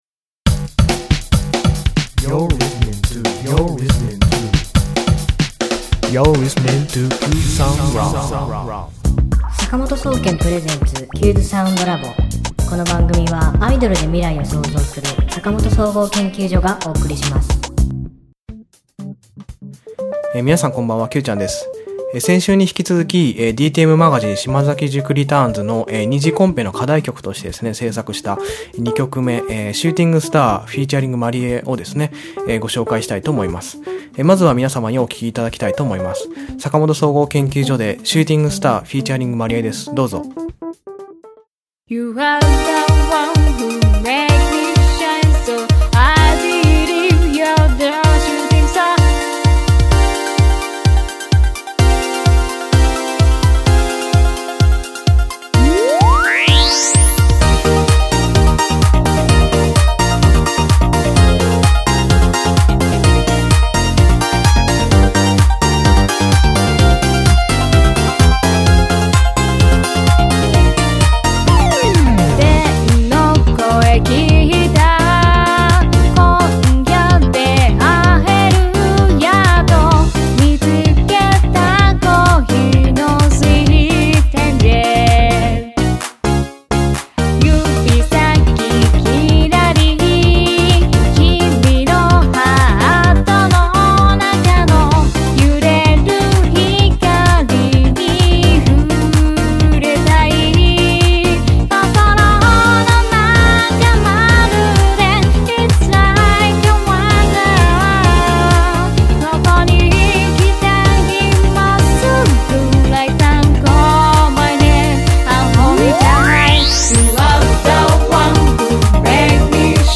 (すべてPC上で制作)